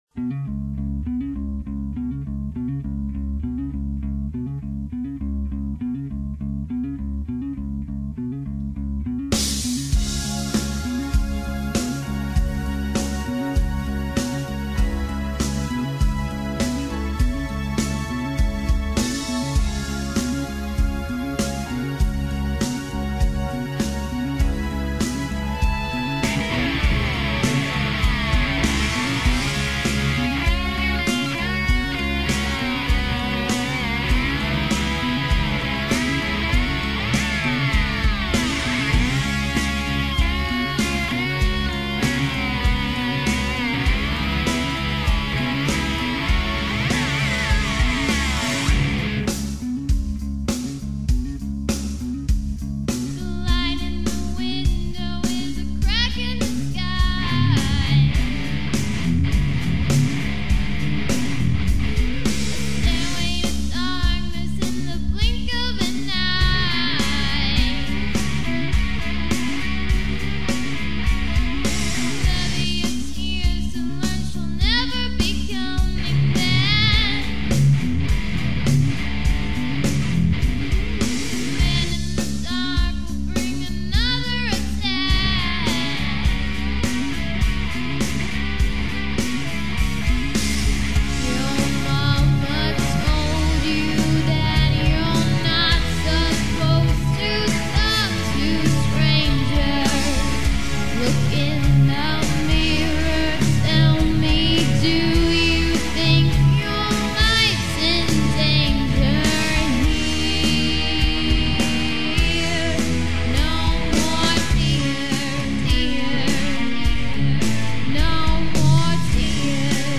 Rockin! :)